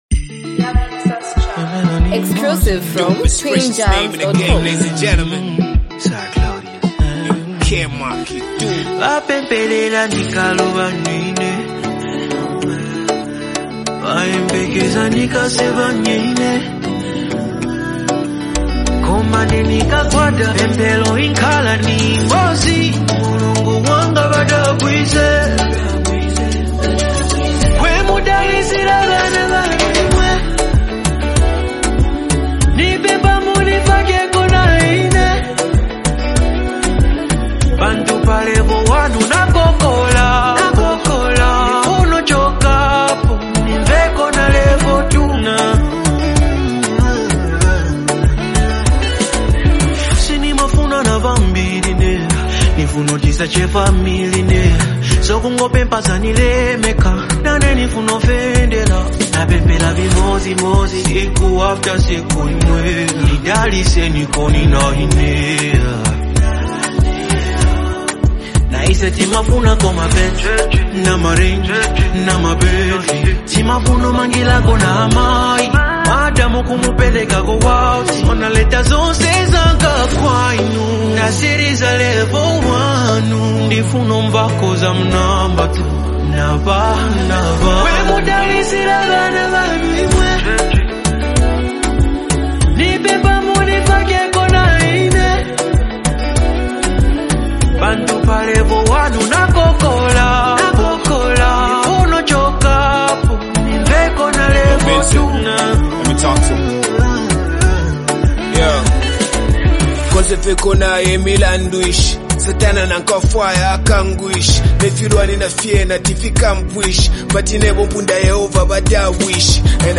high-energy hip-hop track
delivers sharp, confident bars